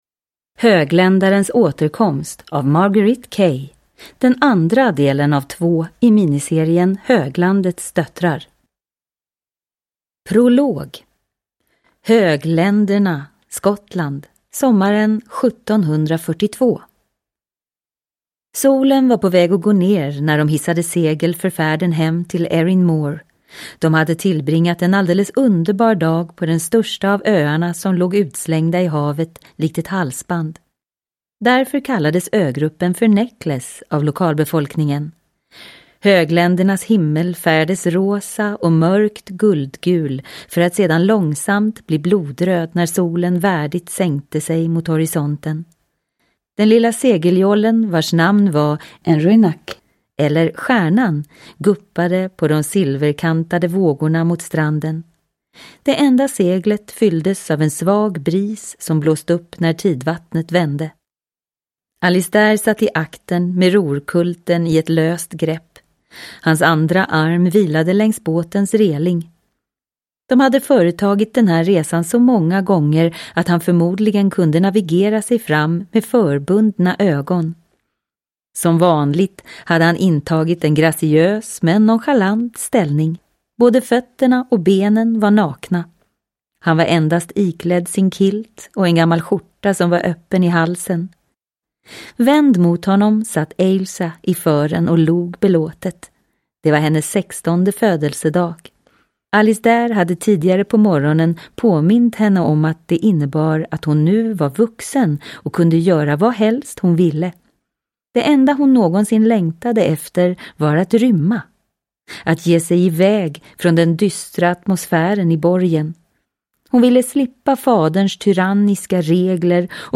Högländarens återkomst – Ljudbok – Laddas ner